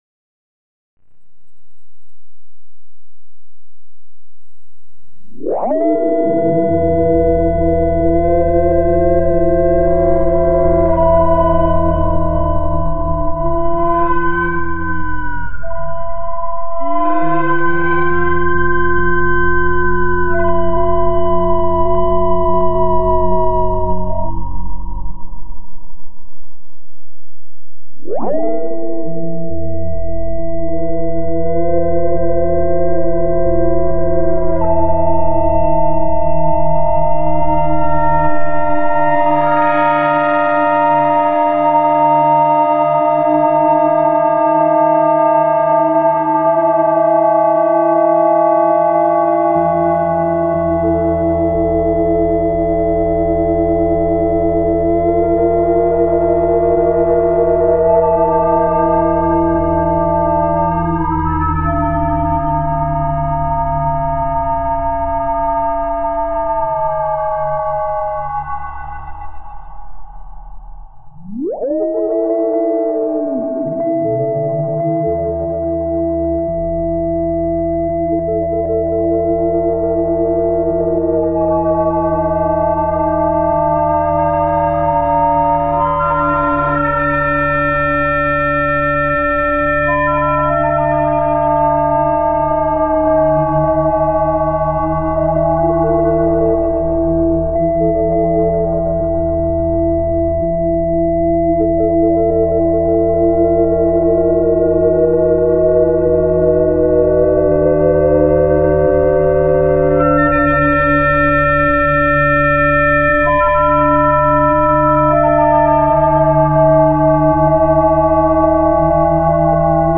Just a quick feedback experiment with Vulcano 3 over iPad speaker and mic, added Eventide crystals and some AUM controls in the path. Used for modulation, two LFOs and one envelope follower to lowpass cutoff. Added some compression post-recording.